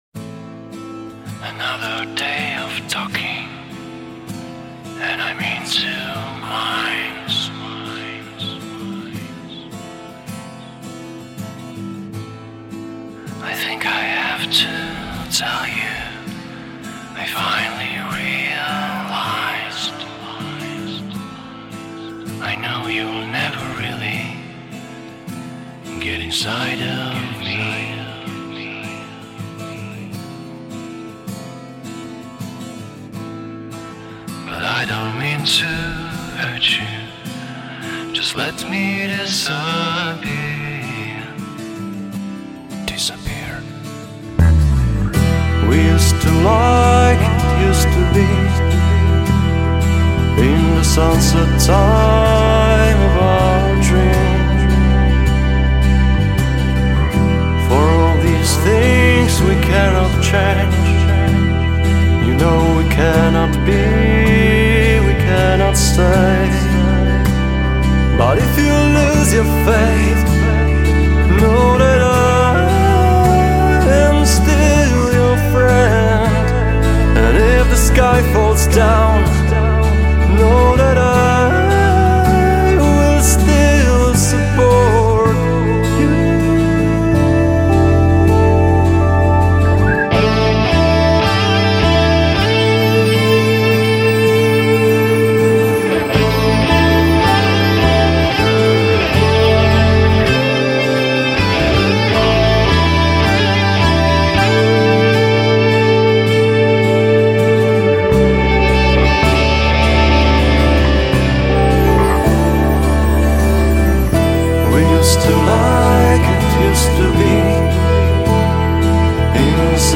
progressive rock band
a more mellow, melodic and acoustic sound